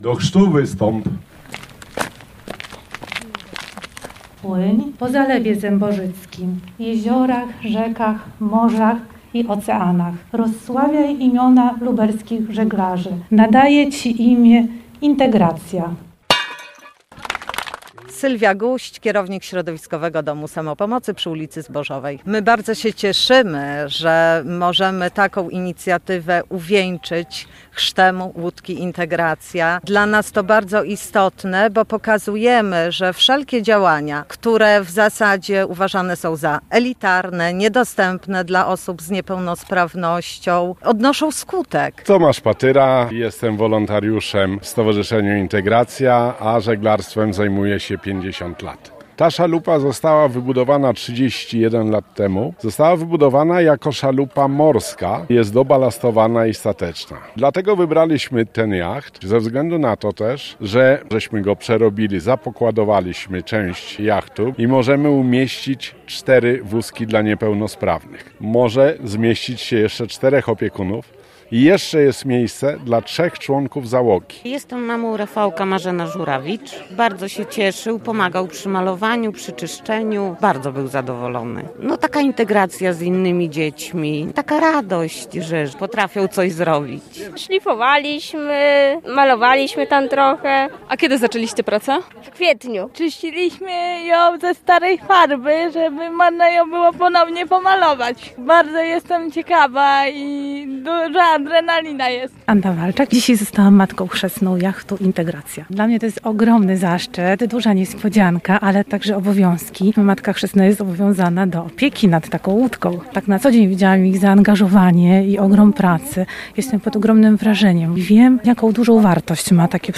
Dziś (12.09.) odbyła się ceremonia chrztu jachtu.